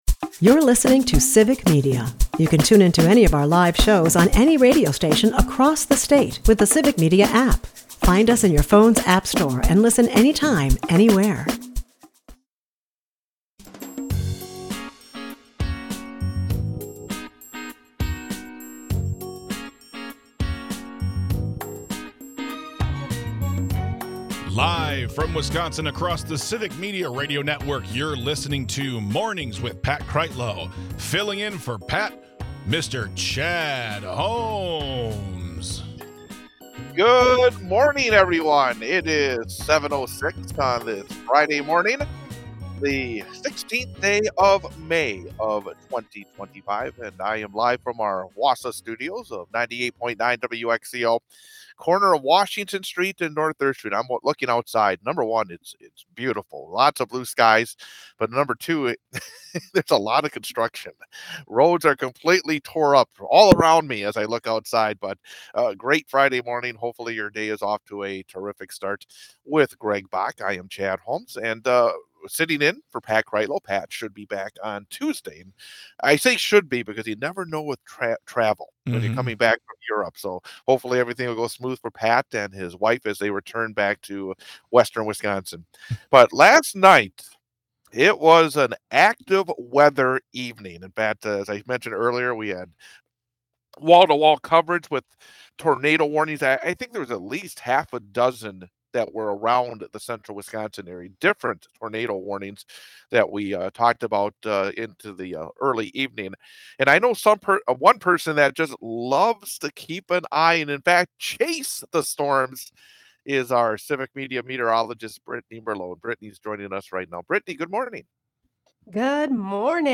Tornadoes wreak havoc across Central Wisconsin, prompting live wall-to-wall coverage of the severe weather.